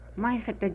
pronounced) — a term for white Americans on account of their cavalry and dragoons being armed with sabers, a strange weapon from the viewpoint of the Indian armory.
Maixete.mp3